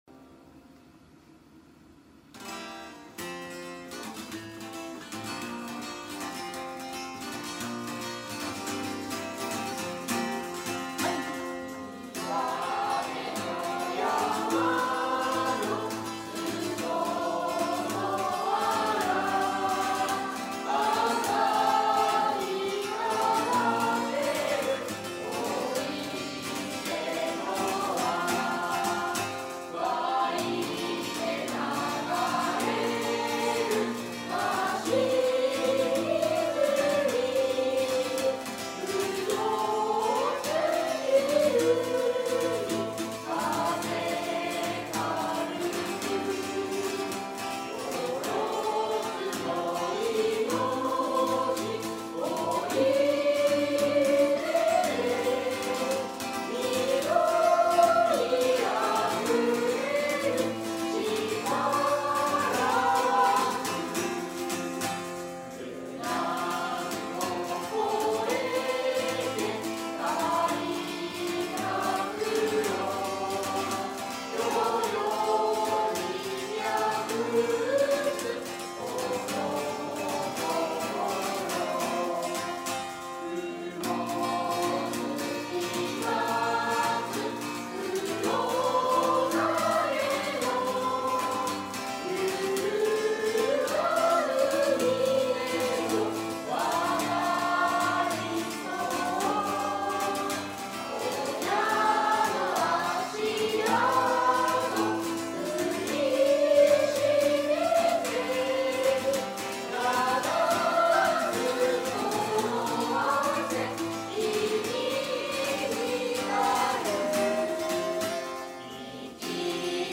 生出讃歌（29日前日練習録音）